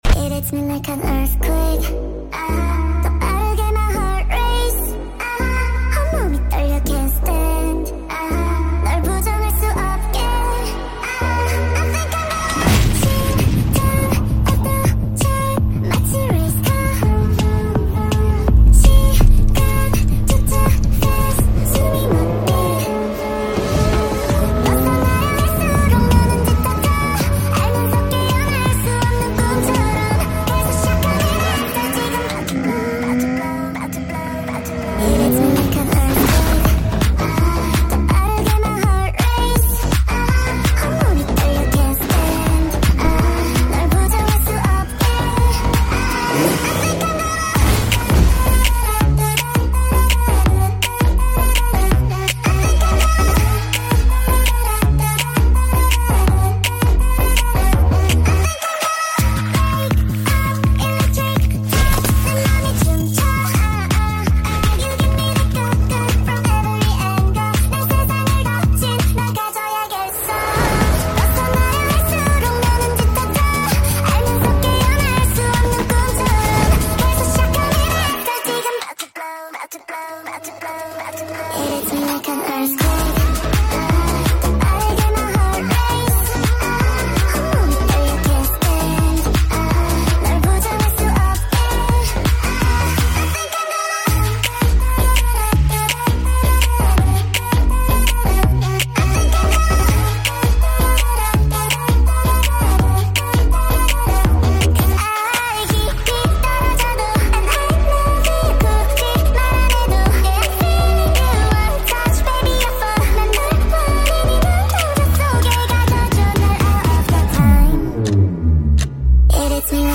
Kpop speed up song